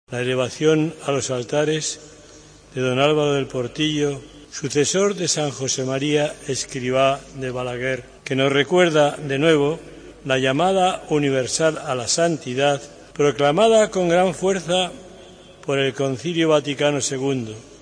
AUDIO: Mons. Javier Echevarría, Prelado del Opus Dei en la beatificación de Don Álvaro del Portillo.